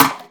VES2 Percussive 048.wav